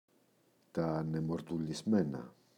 ανεμοτουρλισμένα, τα [anemoturli’zmena]